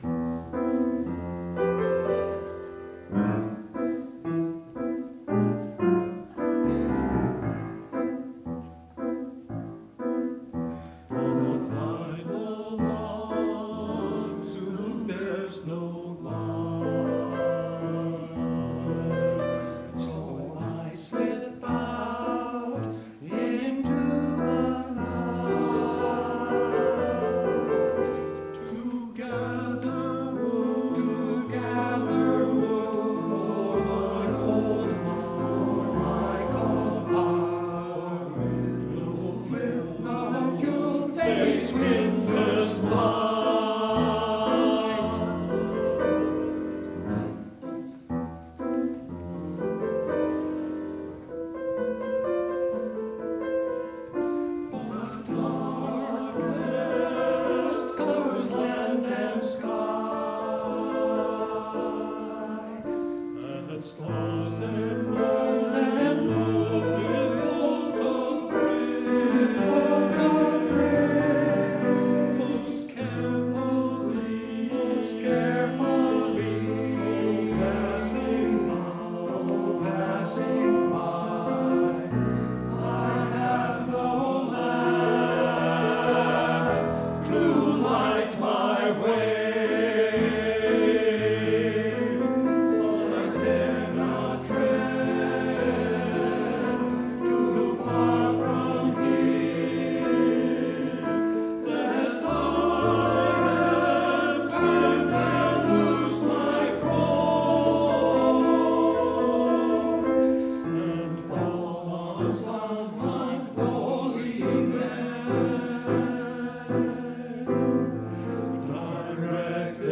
Past QUUF Choir Recordings